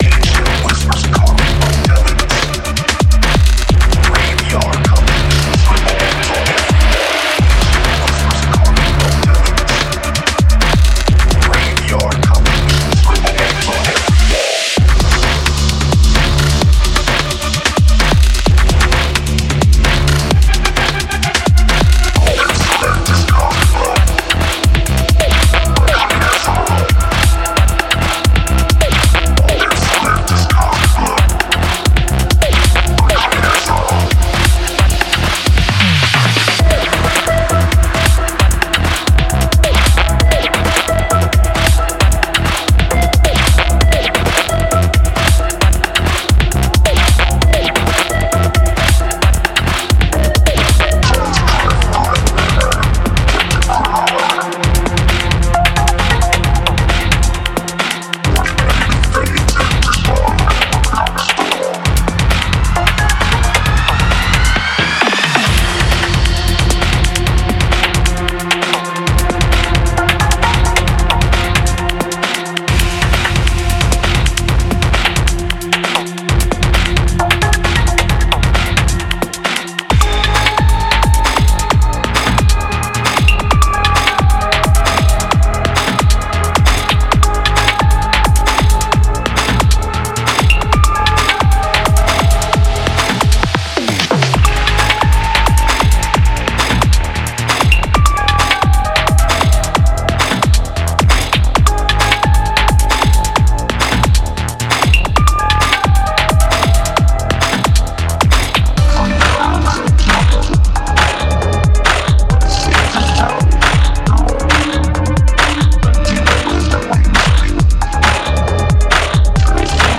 Genre:Electro
すべてのサウンドは制作-readyでありながら、クラシックエレクトロを象徴する生の機械的な雰囲気を忠実に保っています。
24 Vocoder Vocals